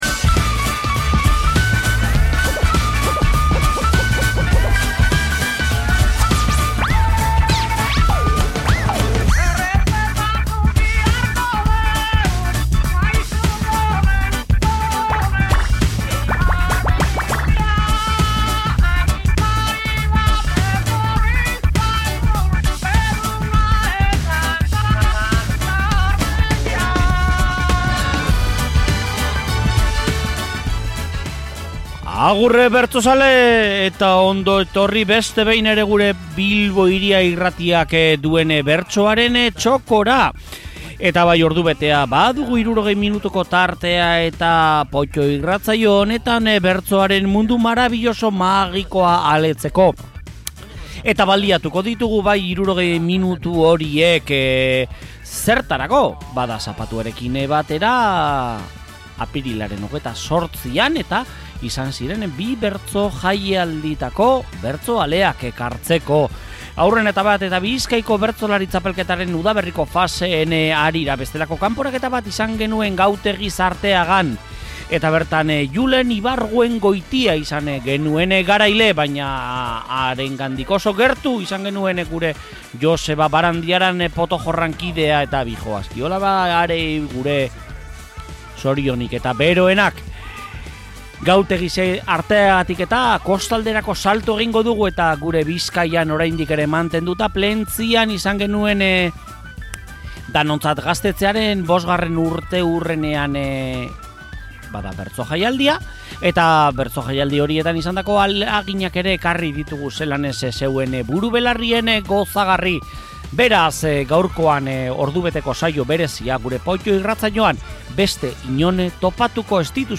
POTO: Bizkaiko Bertsolari txapelketa eta Danontzat Gaztetxearen V. urteurrenean antolatutako bertso-jaialdia
POTTO eta Bilbo Hiria irratiaren primizia mundialak airean! Bizkaiko Bertsolari txapelketaren udaberriko saioen segida izan dugu, zapatuarekin, Gautegiz-Arteagan.
Kostalderako salto txikia egin eta Plentzian izan dugu beste hitzordua.